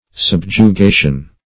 Subjugation \Sub`ju*ga"tion\, n. [Cf. F. subjugation, LL.